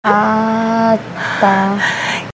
Trecho do episódio "XUXA MENEGHEL" do canal Porta dos Fundos, onde a moradora fica pouco entusiasmada com a Record batendo em sua porta: ah tah.